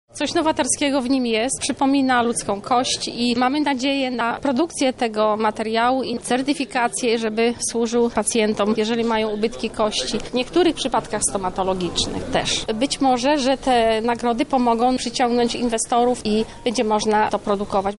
W piątek w hali lubelskich targów odbyła się gala przedsiębiorczości.